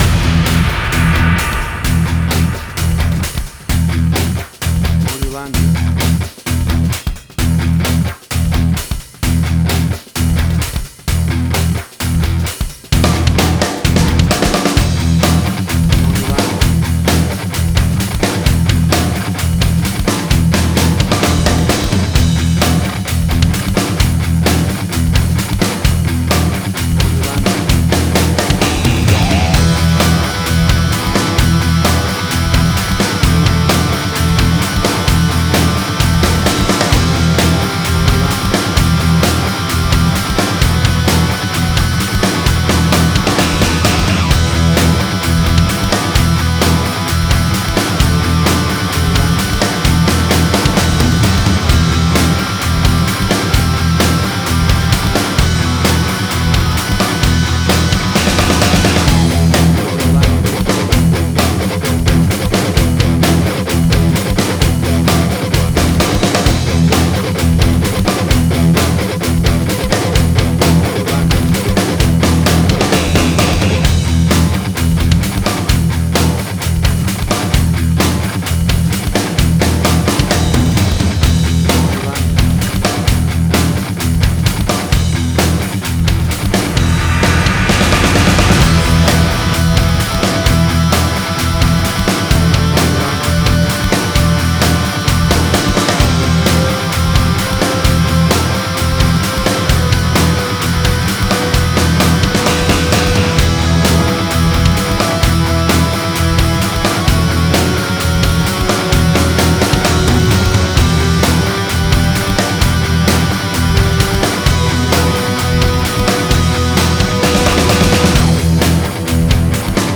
Hard Rock, Similar Black Sabbath, AC-DC, Heavy Metal.
Tempo (BPM): 130